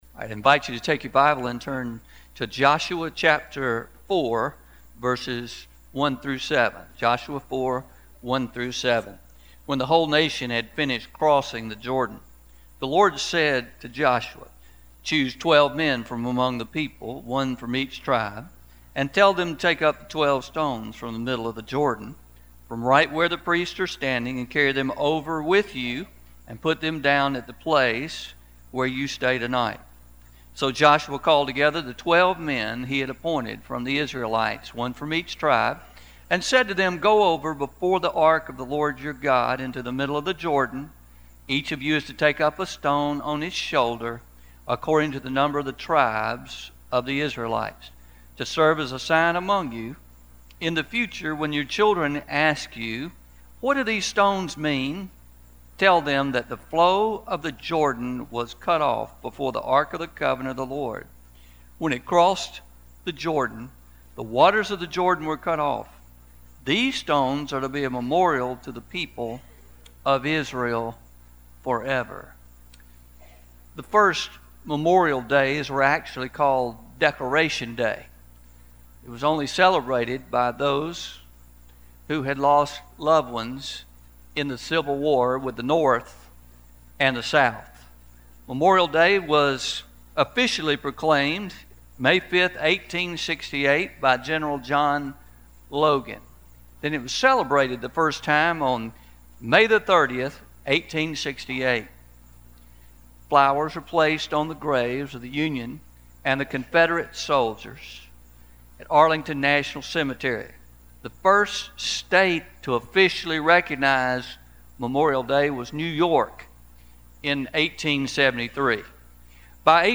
05-26-19am Sermon – The Memorial We Leave